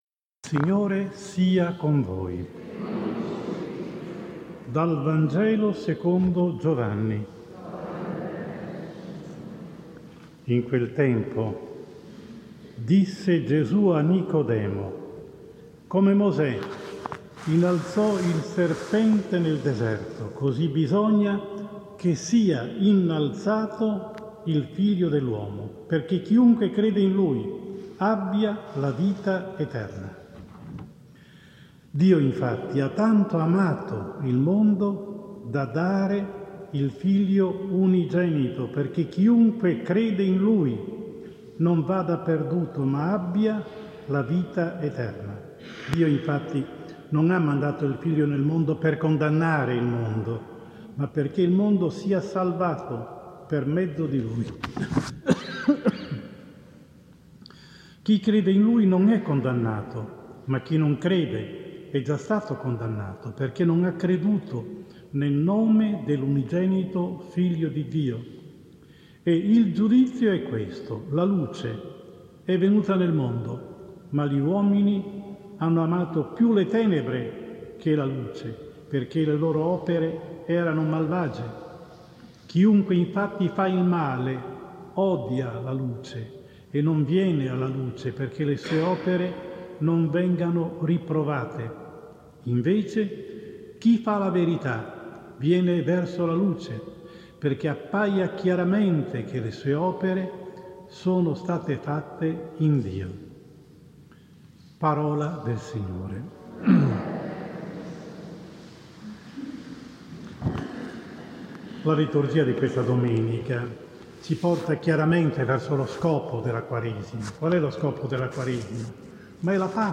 14 Marzo 2021 IV Domenica di Quaresima – Laetare (Anno B): omelia